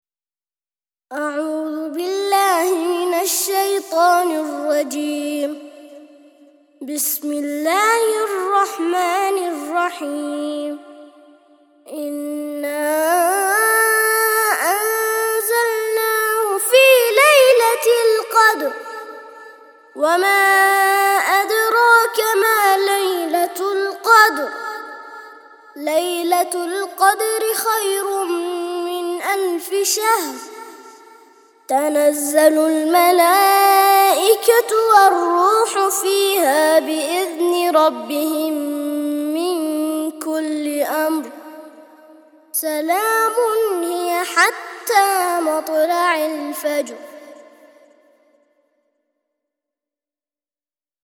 97- سورة القدر - ترتيل سورة القدر للأطفال لحفظ الملف في مجلد خاص اضغط بالزر الأيمن هنا ثم اختر (حفظ الهدف باسم - Save Target As) واختر المكان المناسب